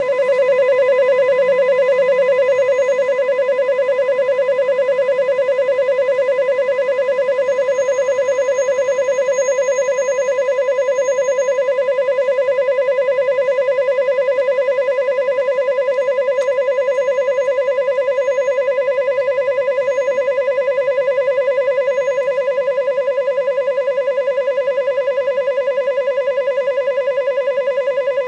Malfunctioning doorbell intercom